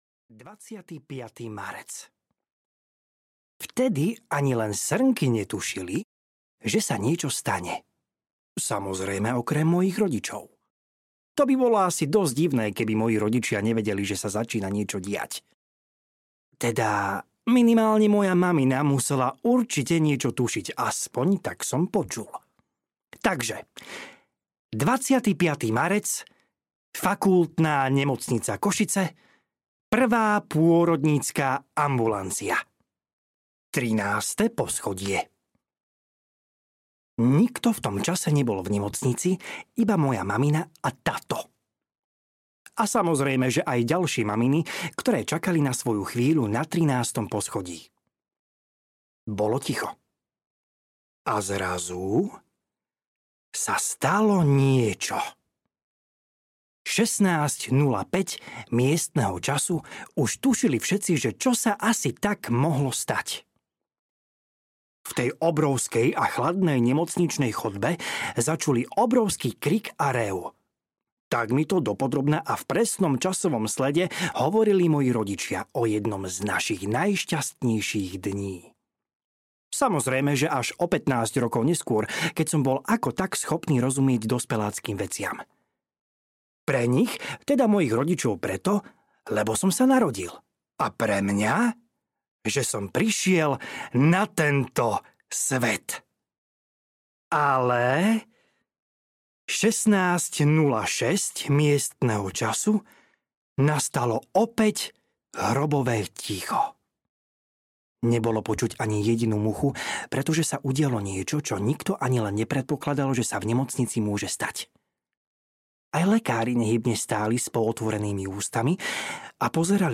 Ukázka z knihy
Zábavnú knižku Trapas môj každodenný som napísal a načítal pre vás milé moje čitateľky a milí moji čitatelia, aby ste si mohli zdvihnúť sebavedomie.
• InterpretLukáš Pavlásek